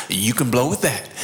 blowthat_s.aiff